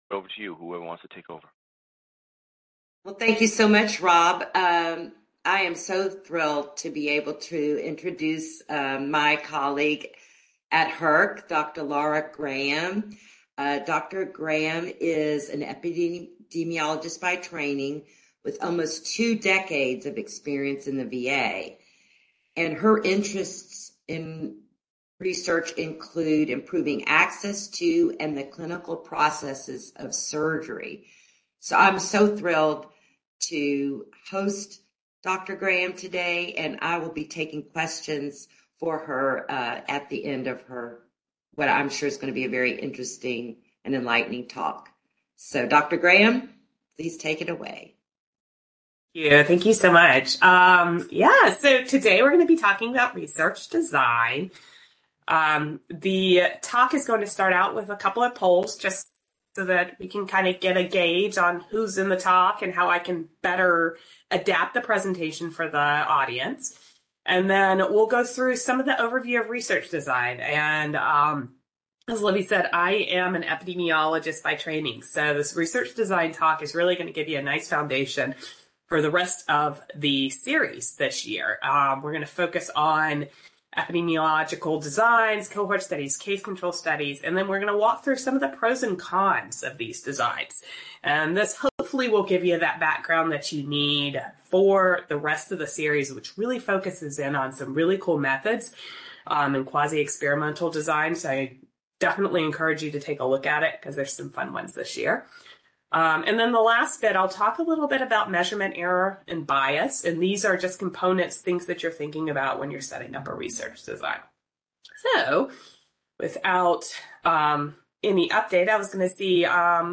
Description: This seminar will provide a basic overview of research designs commonly used in econometric and health services research. We will review the design of cross-sectional, cohort, and case-control studies as well as discuss the advantages and disadvantages of each.